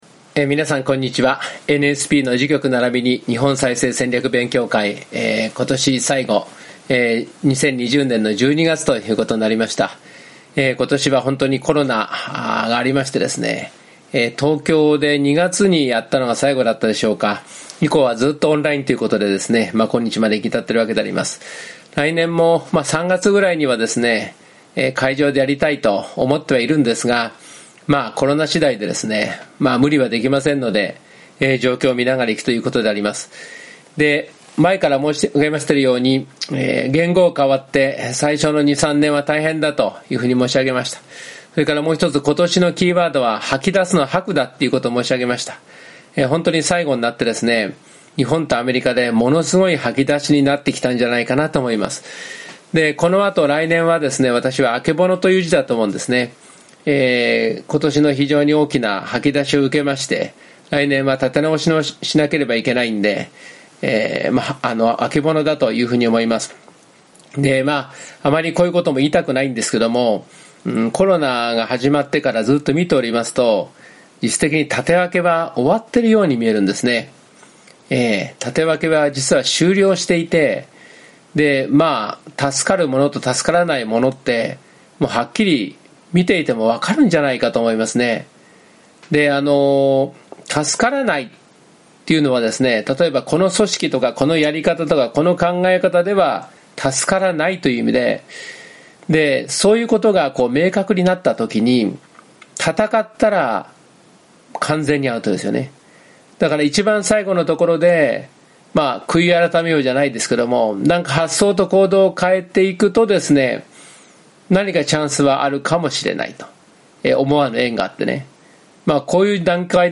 第59回NSP時局ならびに日本再生戦略講演会